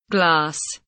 glass kelimesinin anlamı, resimli anlatımı ve sesli okunuşu